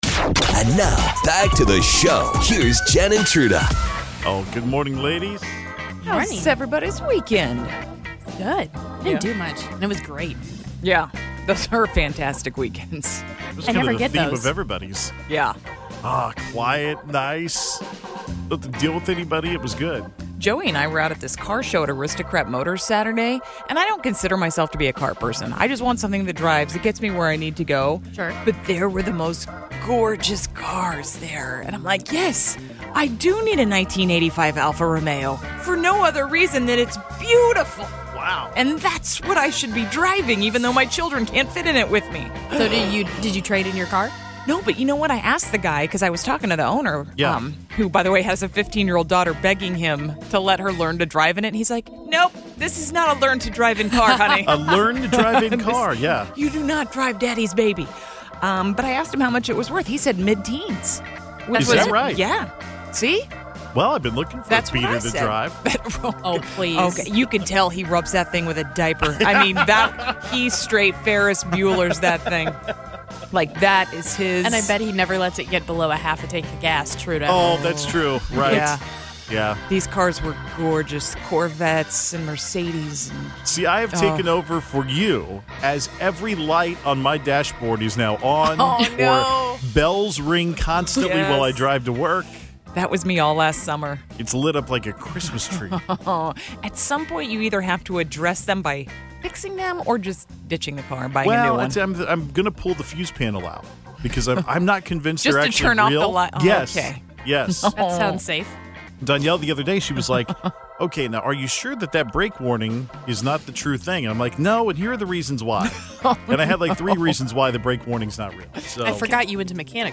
A new season of The Bachelorette premieres tonight, so we revisit past stupidity from the show by using direct quotes from old Bachelor/Bachelorette shows for The Accent Game. Some thing sound even worse in pirate voice!